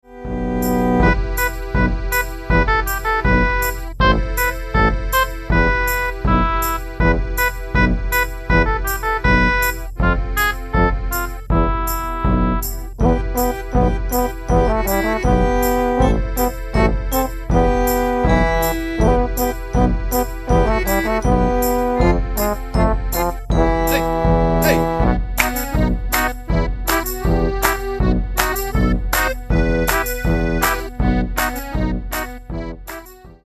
new songs and an Eastern Dance with a few simple steps.